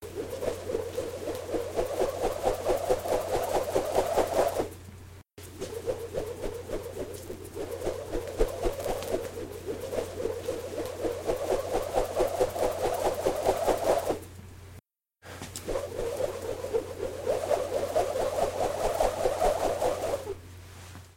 Звуки скакалки
Звук стремительного раскручивания скакалки в руке